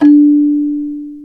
CELESTE 2 D3.wav